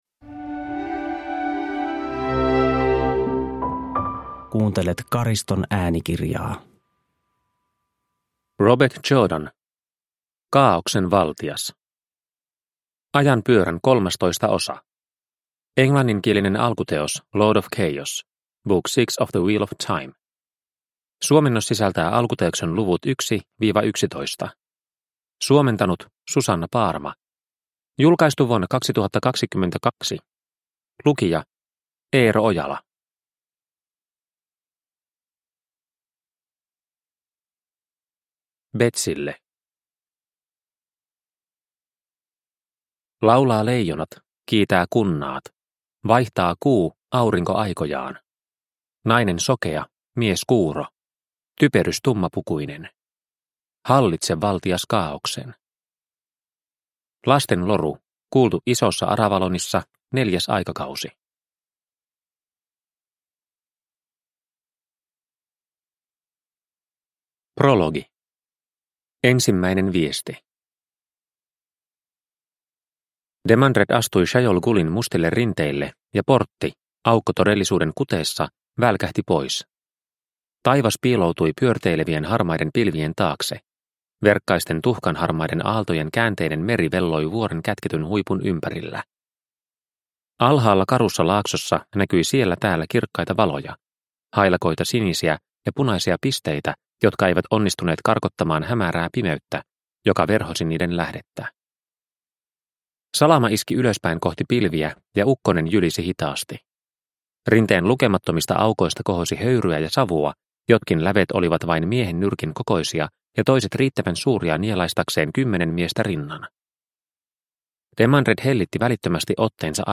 Kaaoksen valtias – Ljudbok – Laddas ner